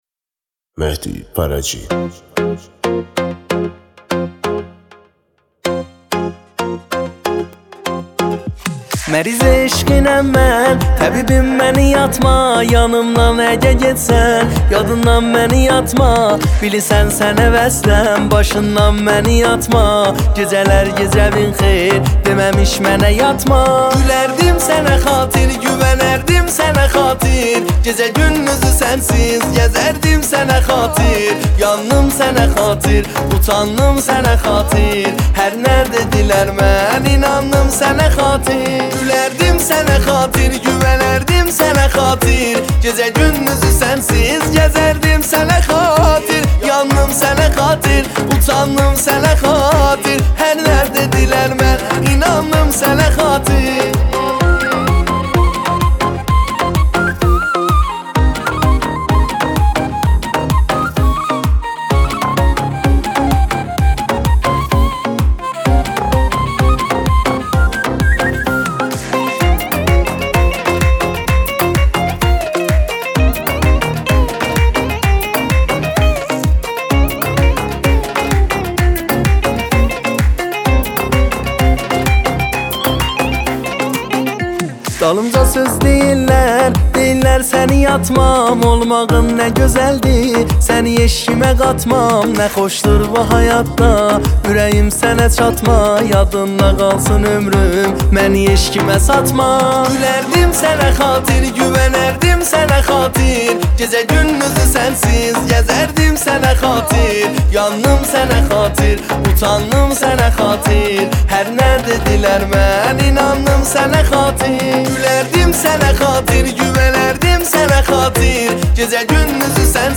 موزیک آذری
شاد آذری